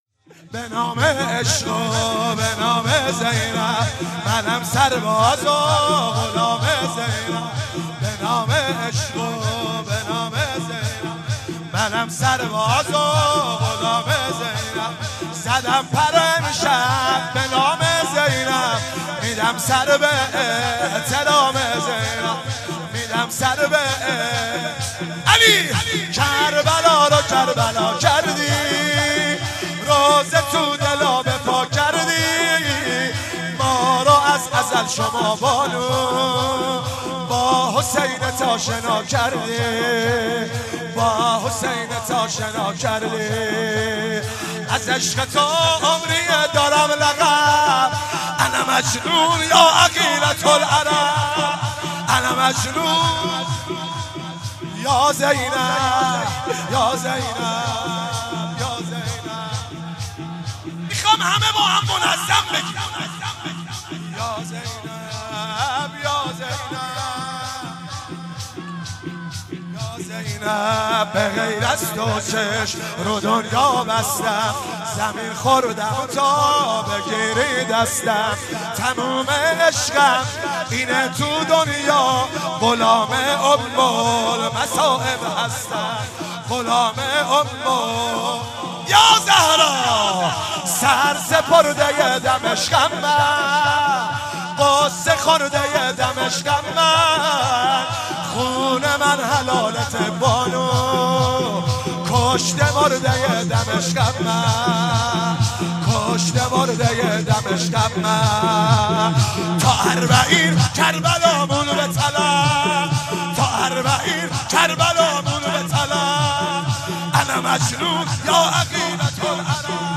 شهادت امام صادق شب دوم 96 -شور - به نام و عشق و به نام زینب
شهادت امام صادق علیه السلام
شور مداحی